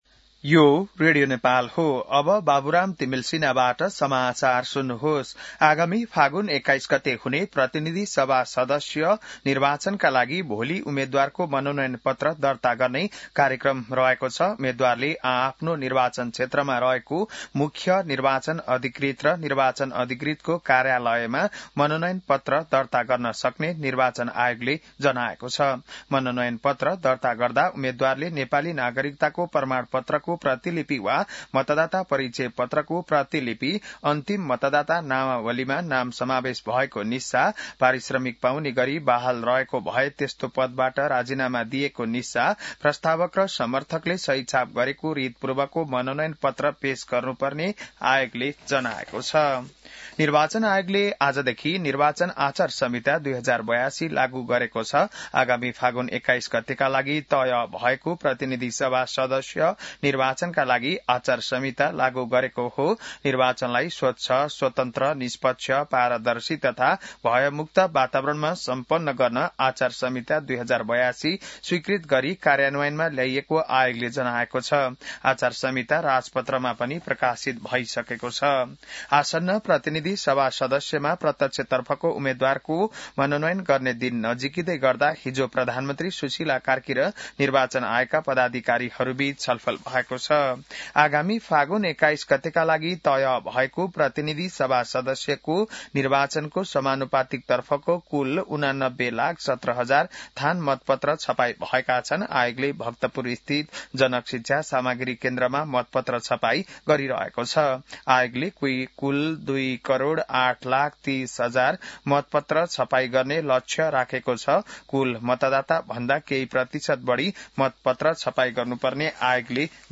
बिहान १० बजेको नेपाली समाचार : ५ माघ , २०८२